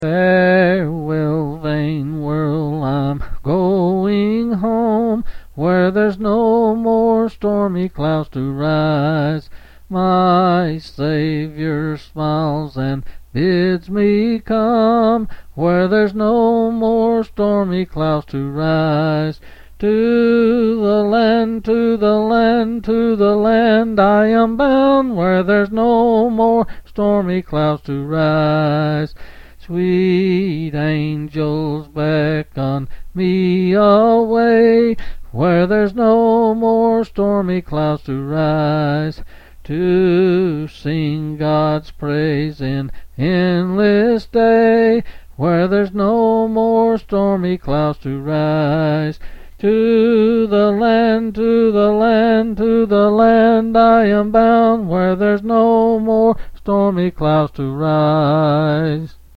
Quill Selected Hymn
L. M.